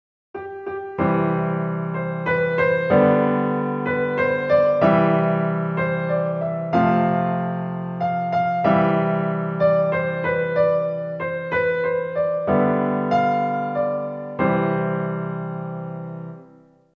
¡Las Mañanitas! Esta canción esta en la tonalidad de C Mayor y se ve y se escucha de esta manera: